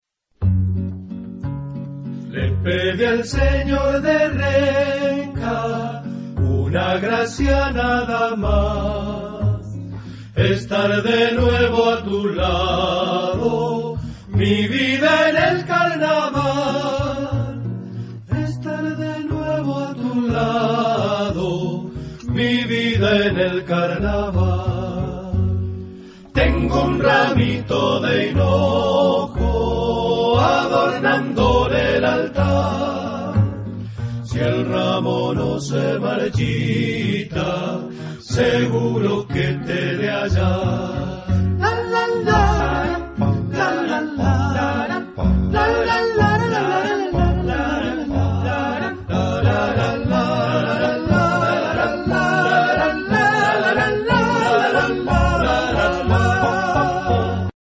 Type de choeur : TTBarBarB  (5 voix égales d'hommes )
Tonalité : mi majeur